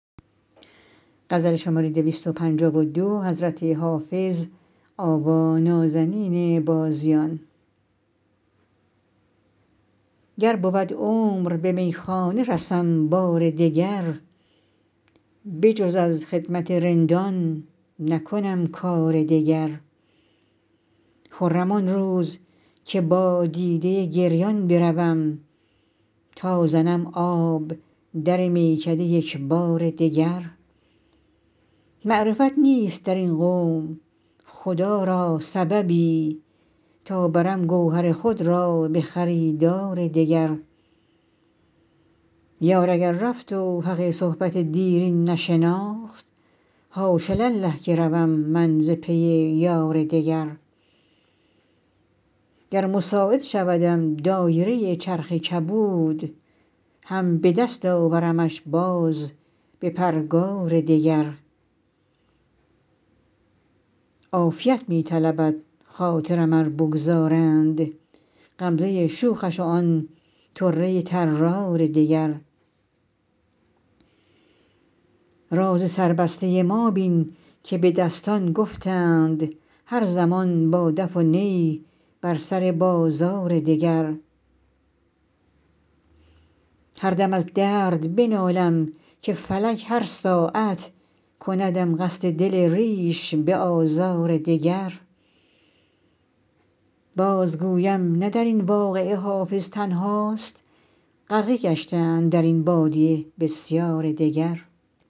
حافظ غزلیات غزل شمارهٔ ۲۵۲ به خوانش